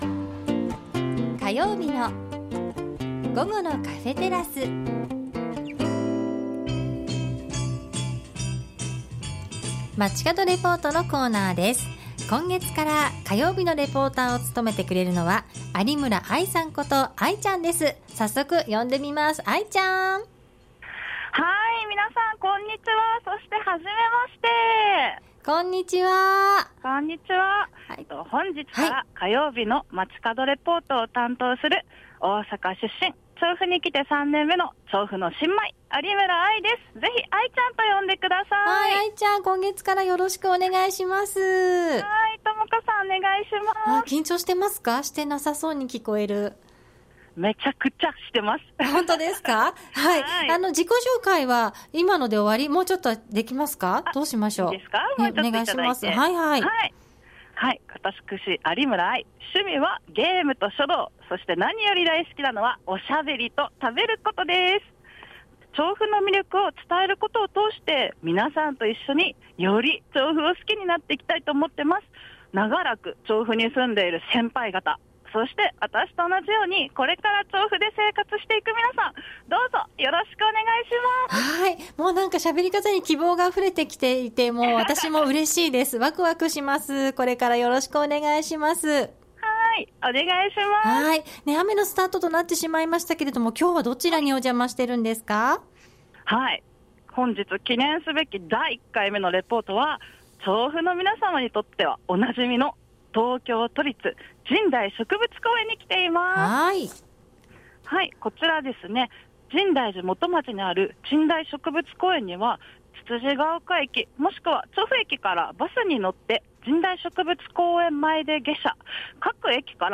冷たい雨が降り、花冷えという言葉がぴったりの４月とは思えない寒さでした。 本日は東京都神代植物公園から現在開催中の「さくらまつり」の楽しみ方をお届けしました。